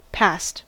Ääntäminen
Ääntäminen US RP : IPA : /pɑːst/ GenAm: IPA : /pæst/ Haettu sana löytyi näillä lähdekielillä: englanti Käännös Adjektiivit 1. hyväksytty Passed on sanan pass partisiipin perfekti.